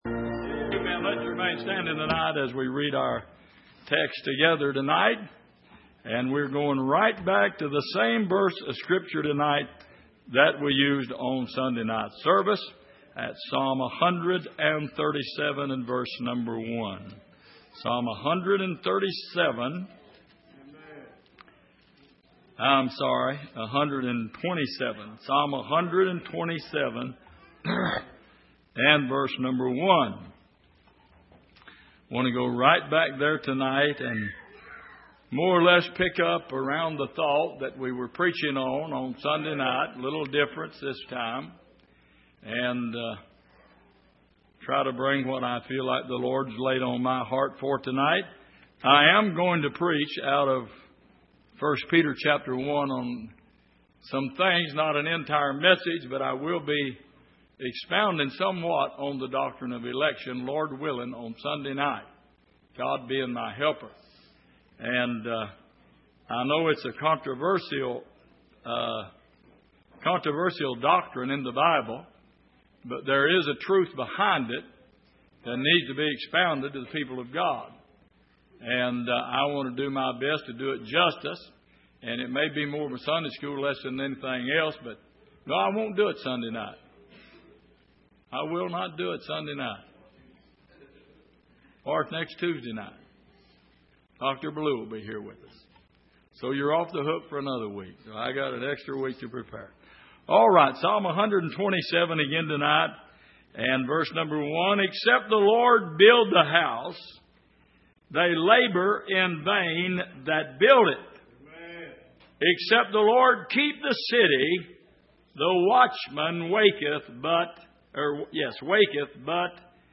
Passage: Psalm 127:1 Service: Midweek